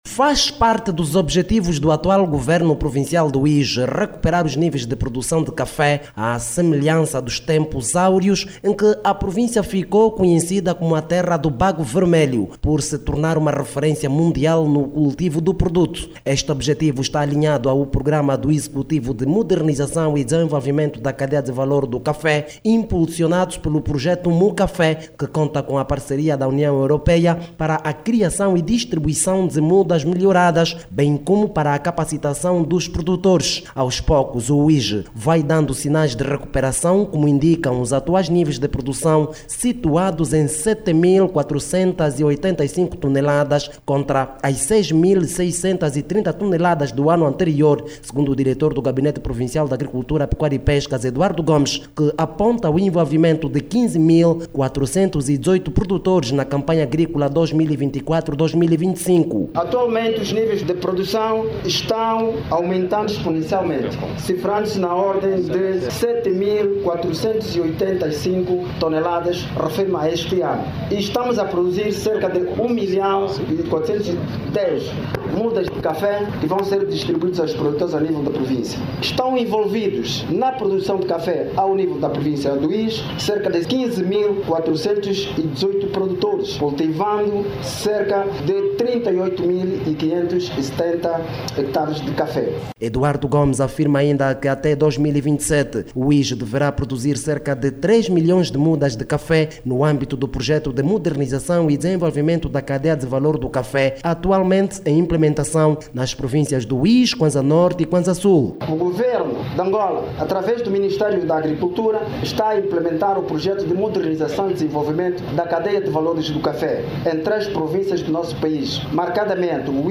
Para atingir este objectivo está em implementação o programa do executivo de modernização e desenvolvimento da cadeia de valor do bago vermelho impulsionado pela União europeia. A reportagem